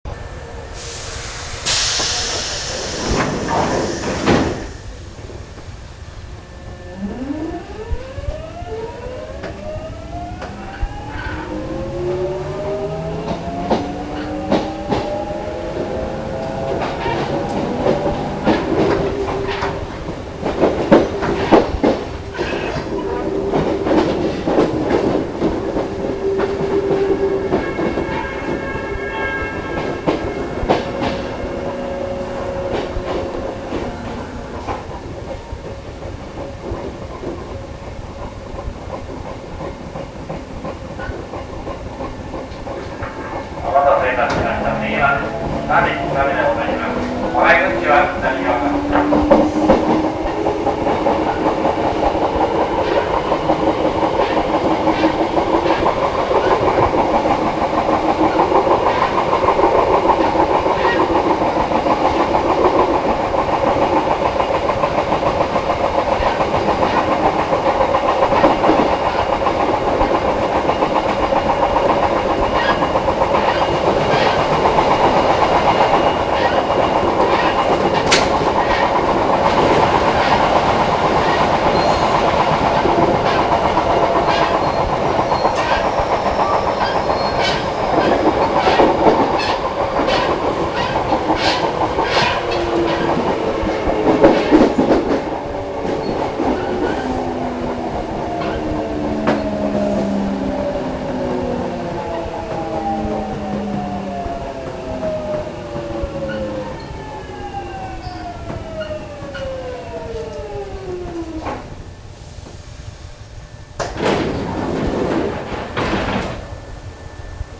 一般の人々の中にも、その独特な走行音に気付いた方もいたのではないでしょうか。
＜走行音＞
貴重な走行音を、たまたま録音していましたので、聞いていただけるようにいたしました。成城学園前−喜多見間（もちろん地上線時代）。